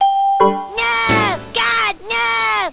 sp_chord2.wav